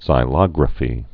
(zī-lŏgrə-fē)